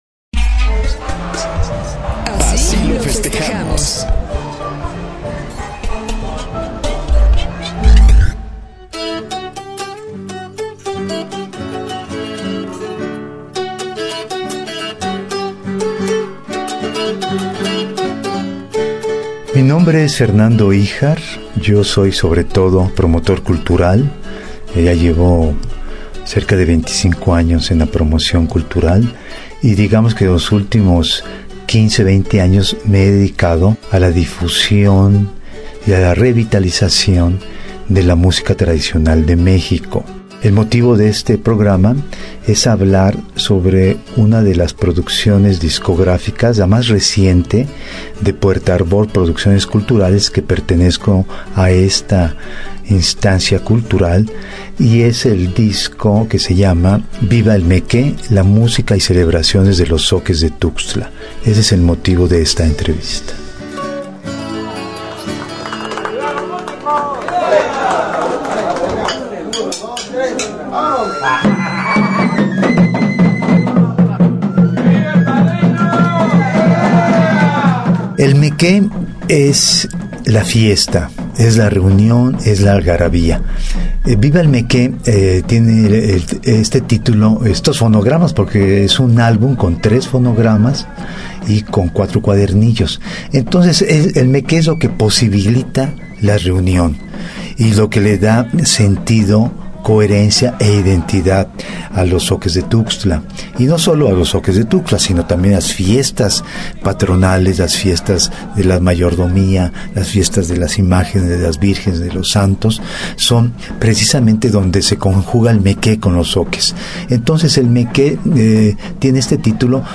La música de pito y tambor y la música de cuerdas que se interpreta en sus celebraciones, se pueden escuchar en este disco, pero también las ceremonias y los rituales captados a través de diversos paisajes sonoros.